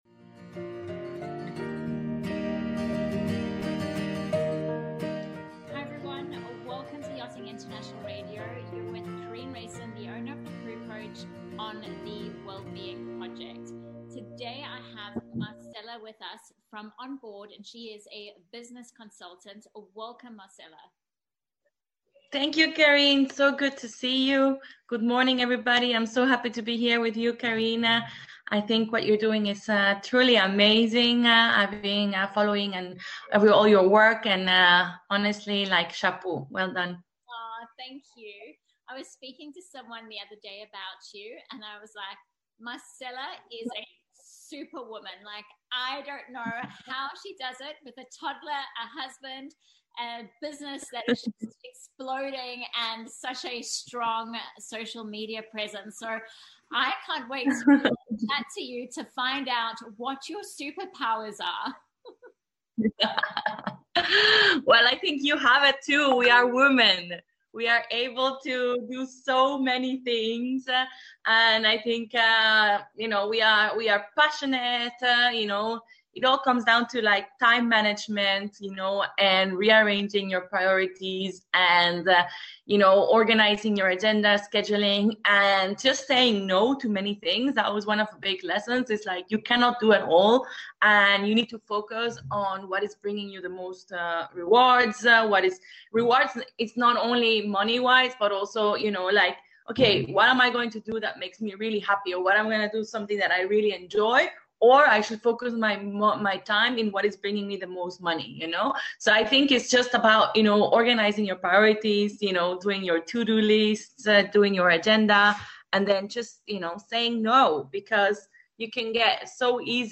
In this interview, we talk about passion, purpose and failure!